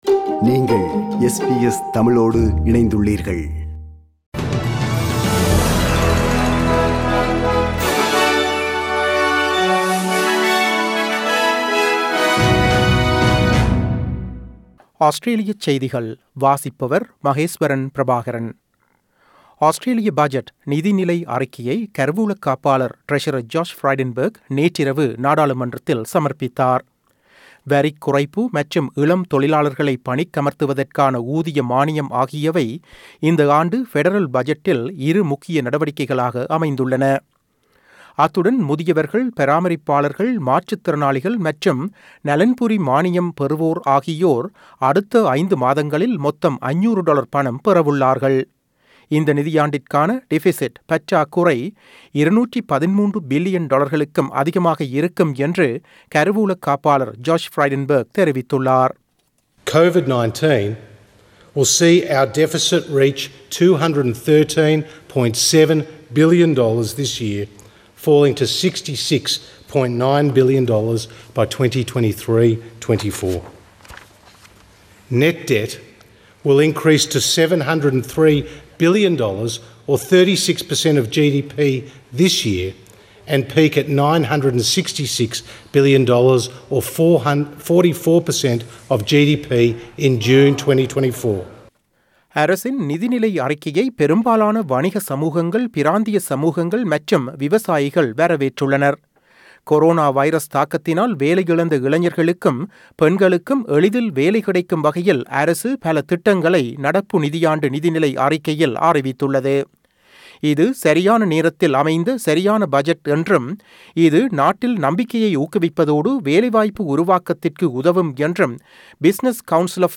Australian news bulletin for Wednesday 07 October 2020.